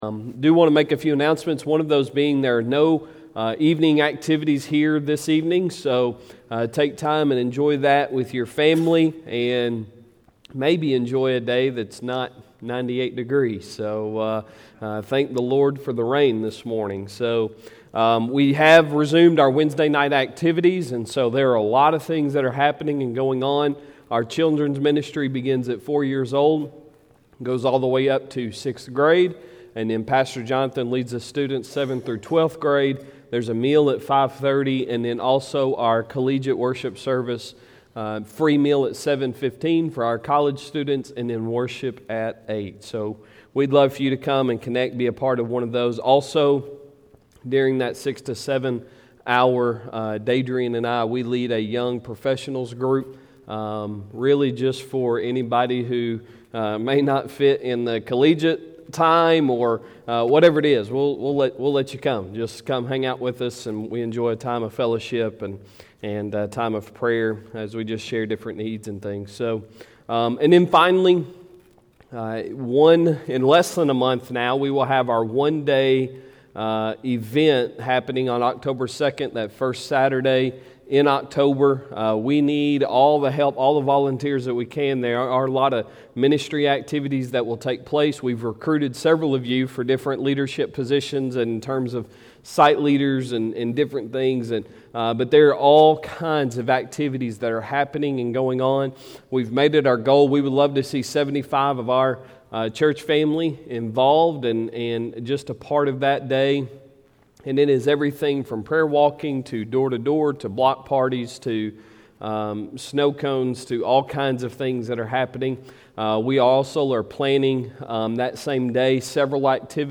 Sunday Sermon September 5, 2021